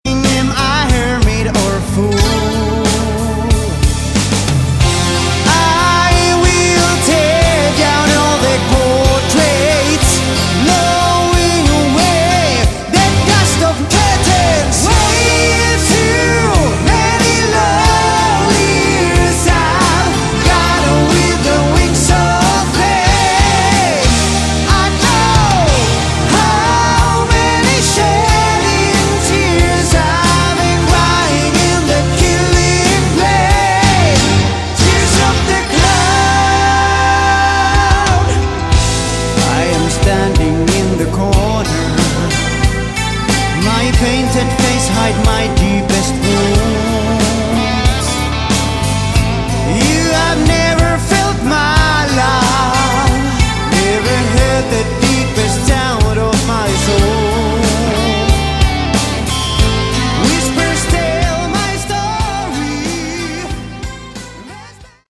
Category: Hard Rock
vocals